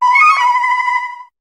Cri de Meloetta dans Pokémon HOME.